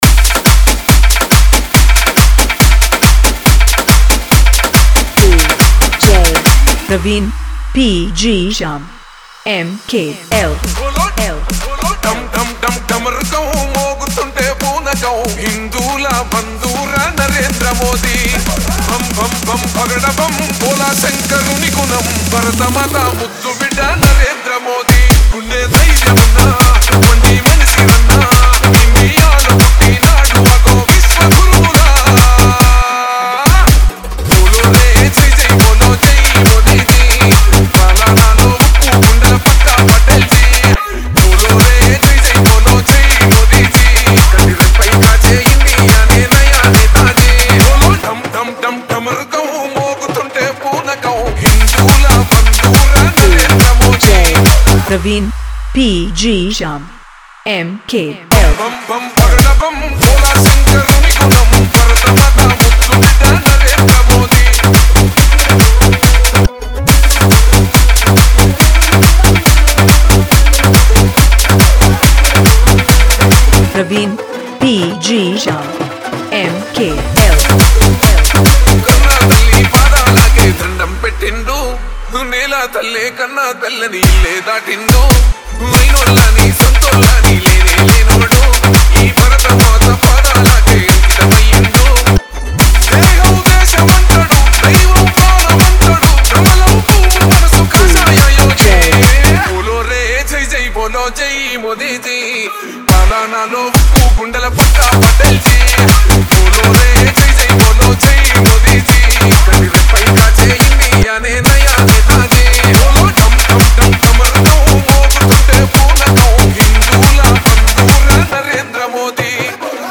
CategoryTelugu Dj Songs